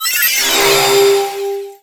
Cri de Cosmovum dans Pokémon Soleil et Lune.